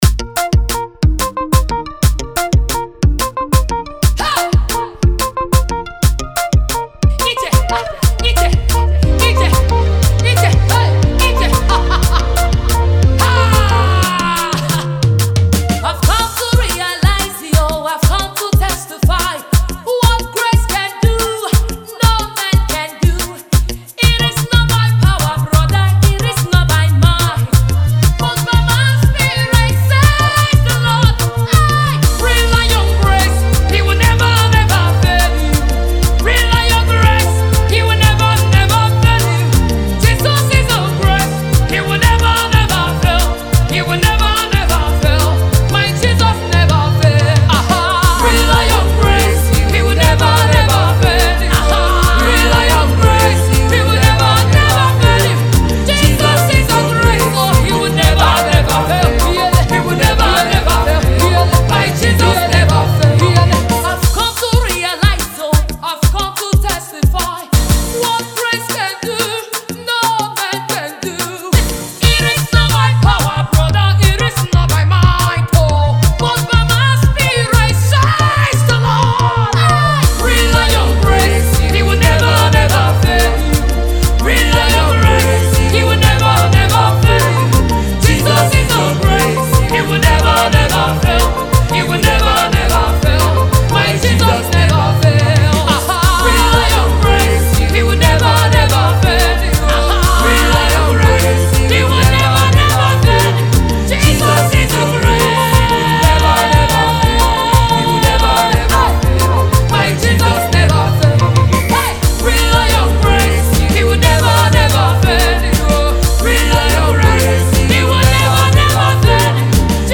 Prophetic Praise